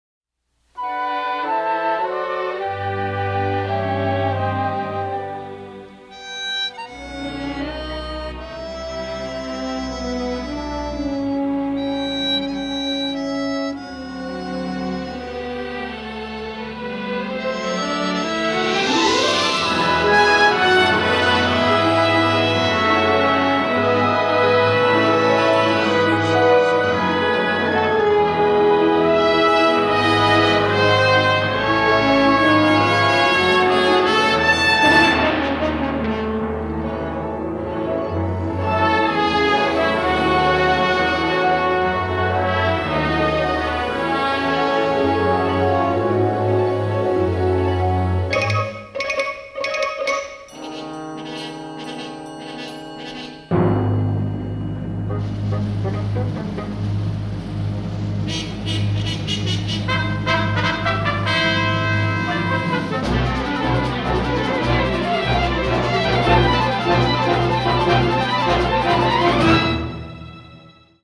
rousingly patriotic score